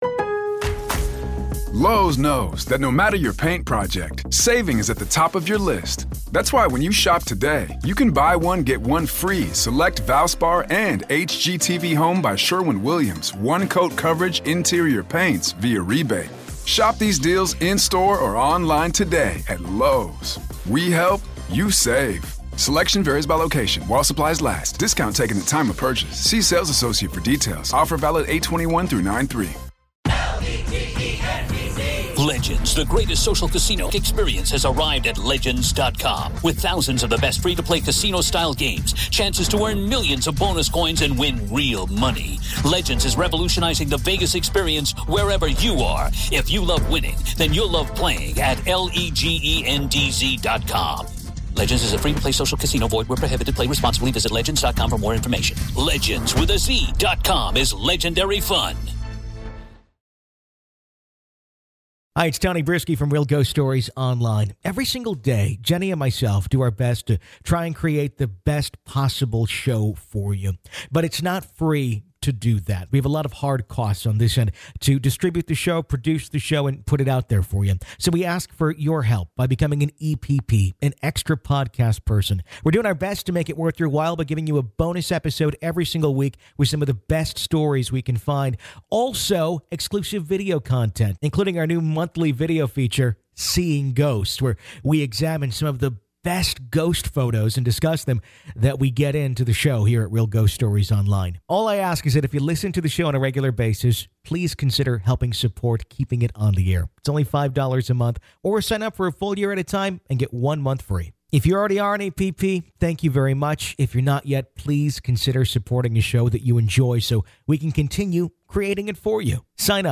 We take your calls and hear your chilling experiences of real life encounters with ghosts and the paranormal.